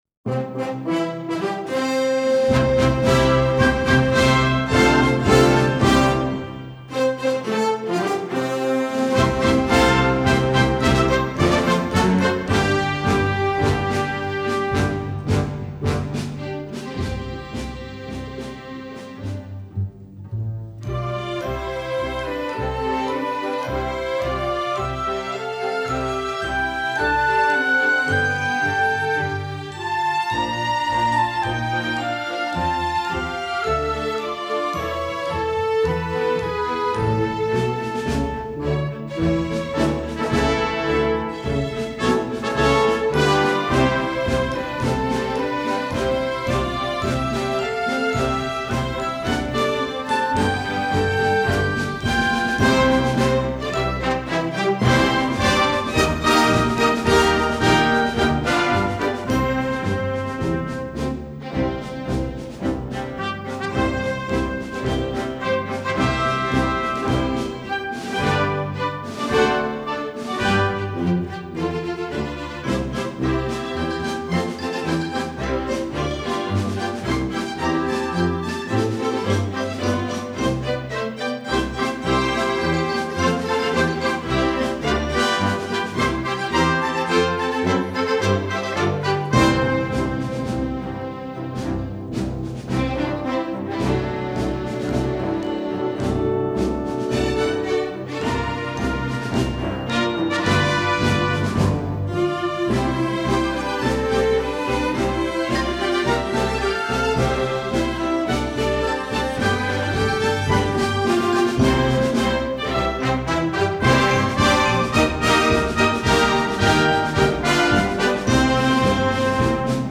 Instrumental Orchestra Full Orchestra
Full Orchestra